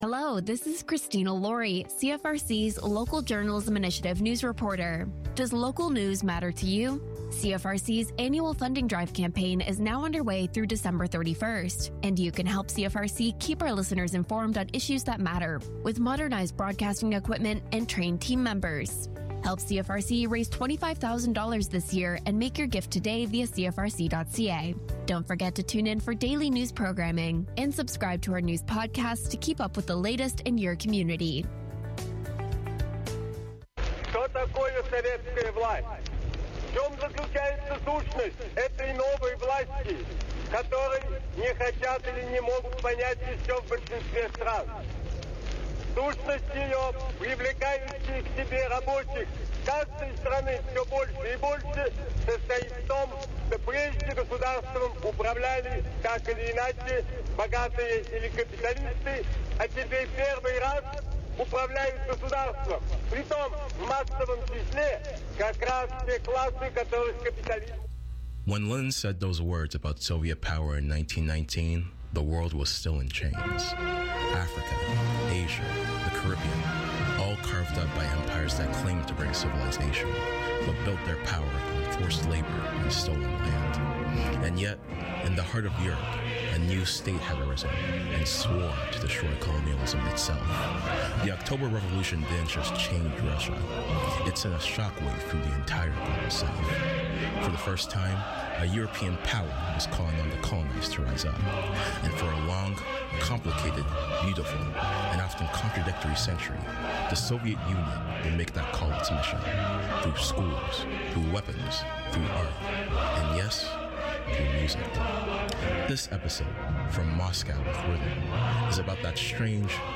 We cover everything from 70s Bossa Nova and Motown, to 80s disco, and to Hip-Hop and Afrobeats of the 2000s. Sounds of the Diaspora both celebrates black music & culture, but also seeks to educate about the socio-political history behind the beats.